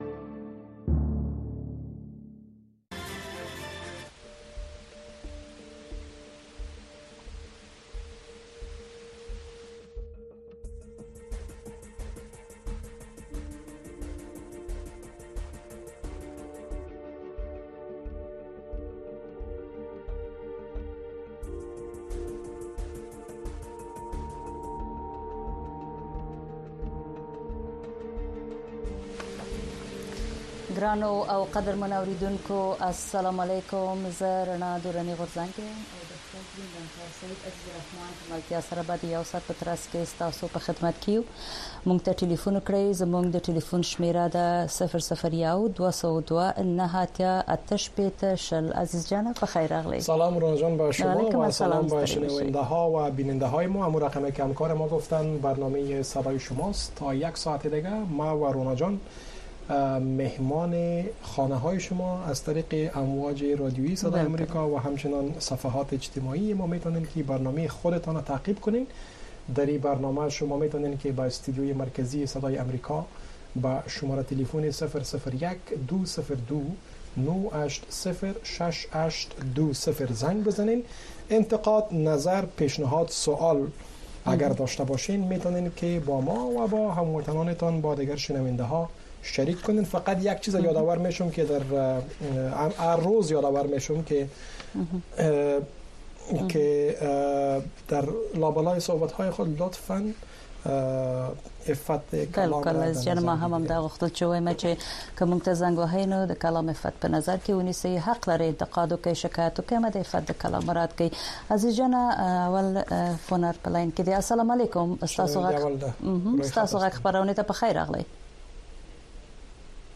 در برنامۀ صدای شما، شنوندگان رادیو آشنا صدای امریکا مستقیماً با ما به تماس شده و نگرانی‌ها، دیدگاه‌ها، انتقادات و شکایات شان را با گردانندگان و شنوندگان این برنامه در میان می‌گذارند. این برنامه به گونۀ زنده از ساعت ۹:۳۰ تا ۱۰:۳۰ شب به وقت افغانستان نشر می‌شود.